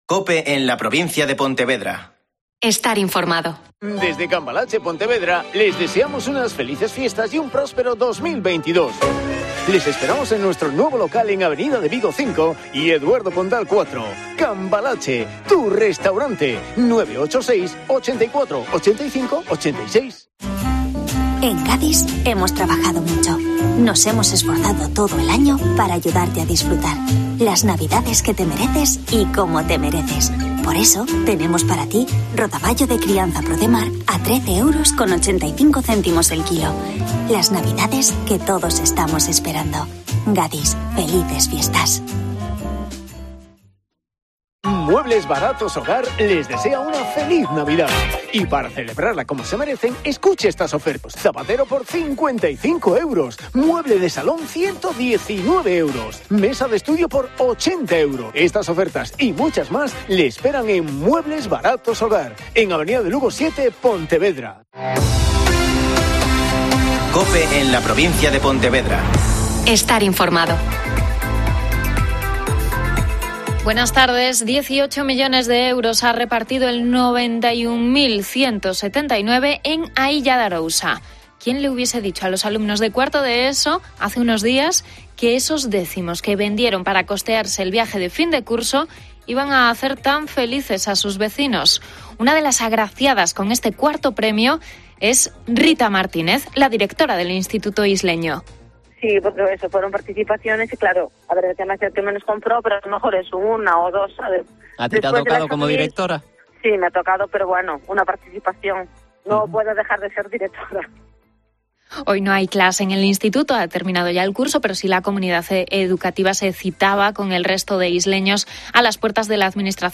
Mediodía COPE en la Provincia de Pontevedra (Informativo 14:20h)